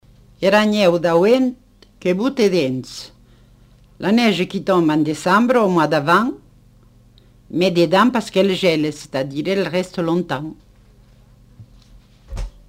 Lieu : Montauban-de-Luchon
Effectif : 1
Type de voix : voix de femme
Production du son : récité
Classification : proverbe-dicton